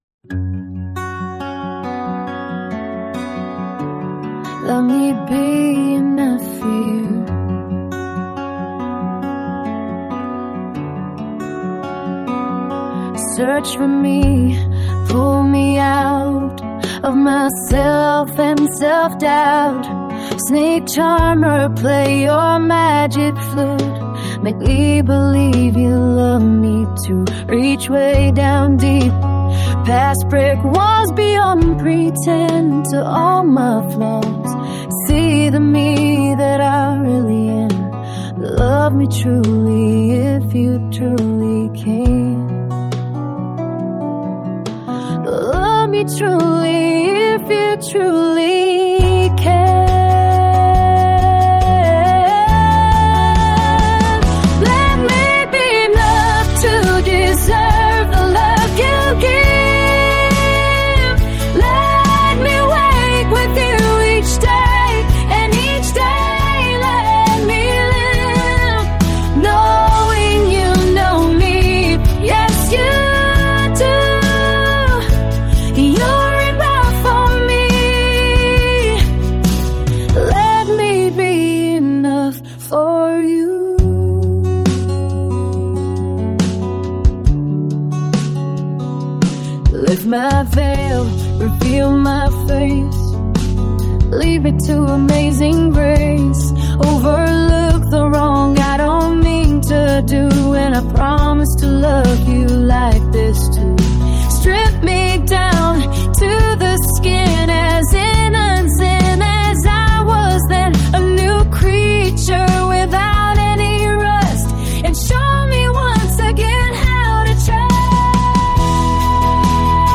The lyrics and song style/ melody are all my own creation but the music and vocals are from an app i use to make song demos